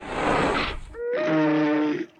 Мамонт - Альтернативный вариант